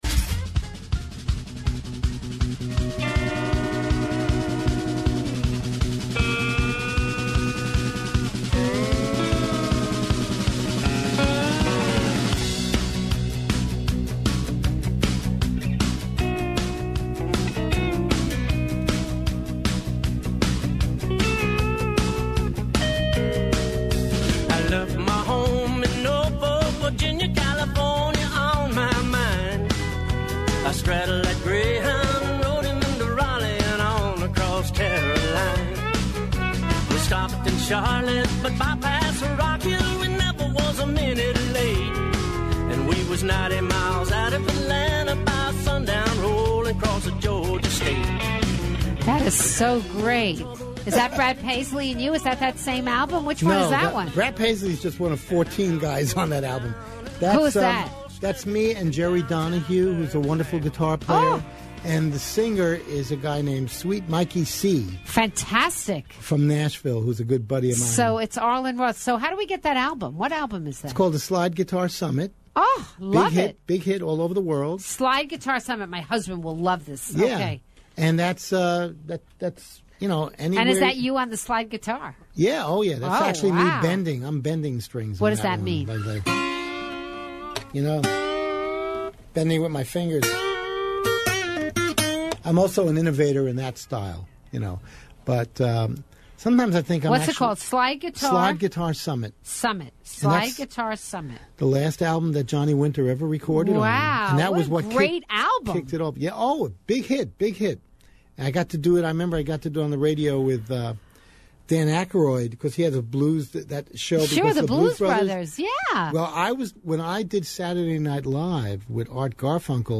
Guitarist Arlen Roth